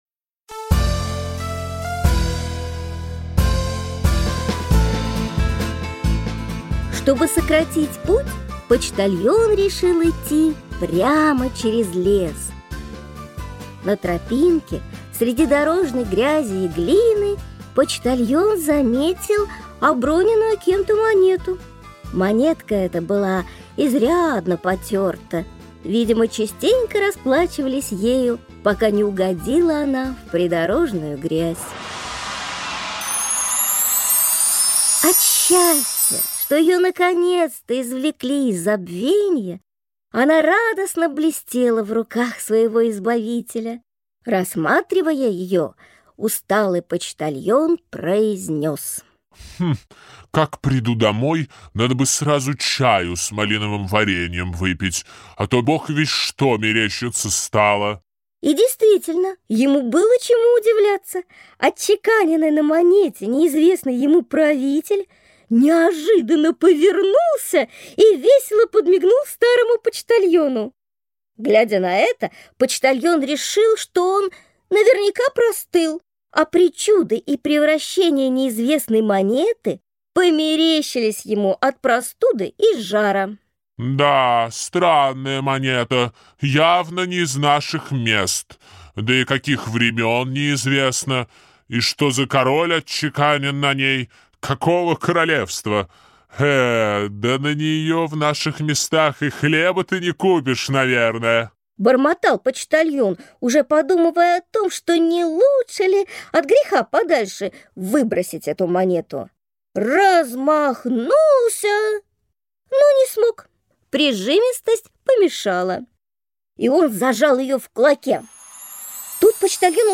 Аудиокнига Монетка | Библиотека аудиокниг